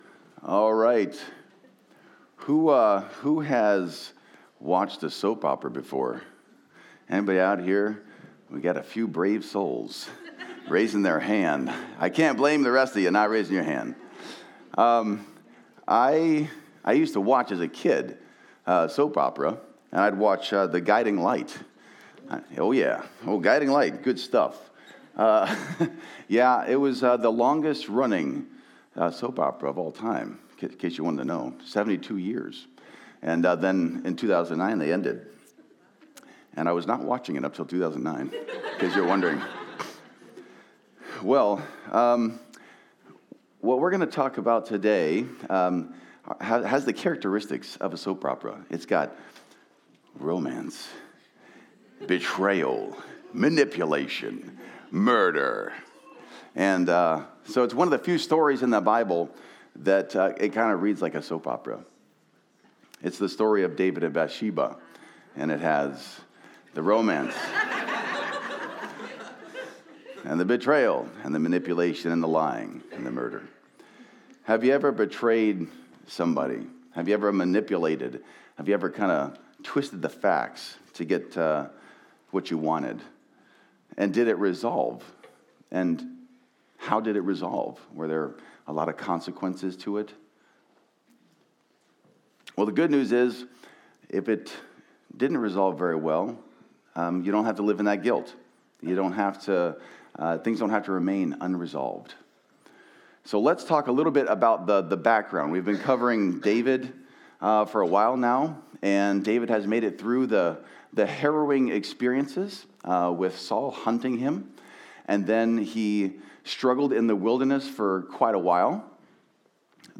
Download Audio Home Resources Sermons David: David’s Descent Jan 26 David: David’s Descent Learn how to find forgiveness and faithfulness from one of the most heartbreaking and tragic stories of the Bible.